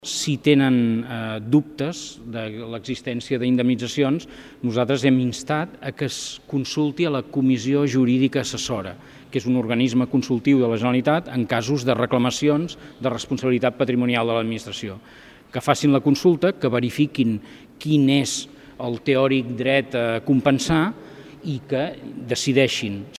s’ha celebrat durant la Comissió de Territori del Parlament